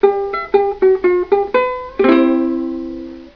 vamp2.aiff